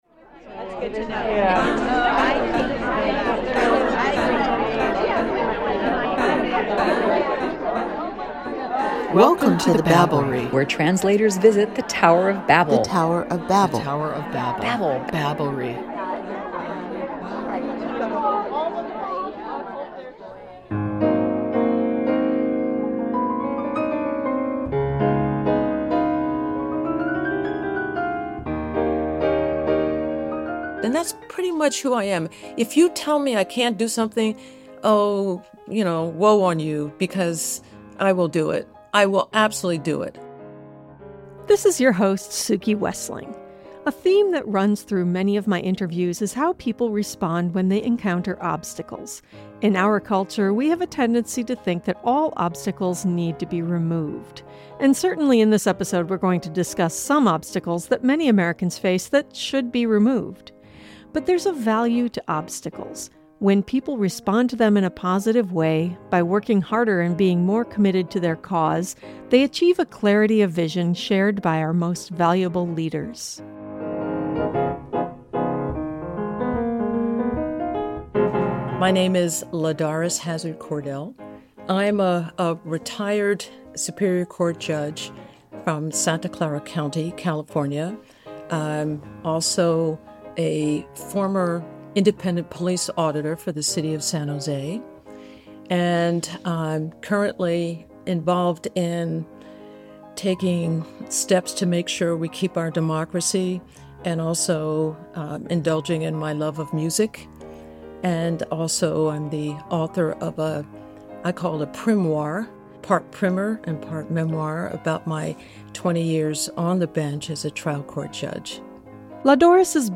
Retired judge LaDoris Cordell is known for her groundbreaking career as a judge in Northern California and then her stint as Independent Police Auditor for the City of San Jose. Join us in a wide-ranging conversation, from a segregated childhood, an unusual education, and an unlikely acceptance to Stanford Law School, to retired judge, community activist, musician, artist, and grandmother.